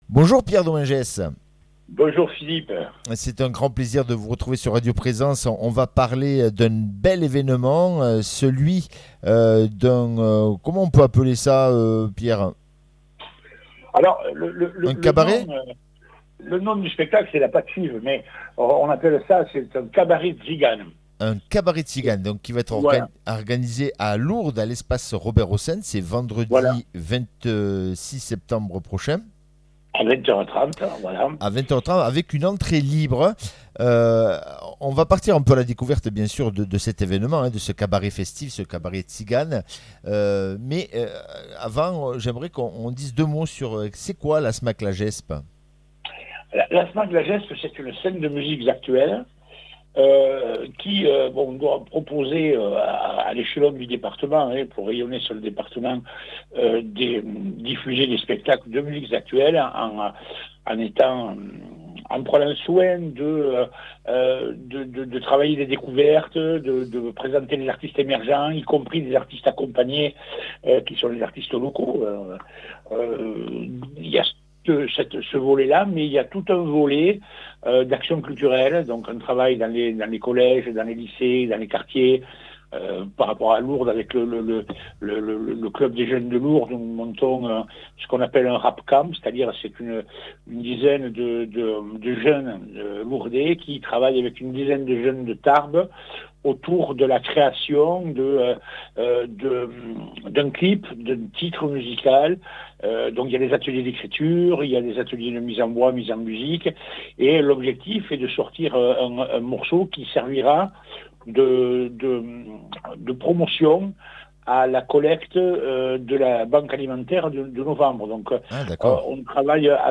Interview et reportage du 22 sept.